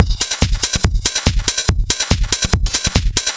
Index of /musicradar/uk-garage-samples/142bpm Lines n Loops/Beats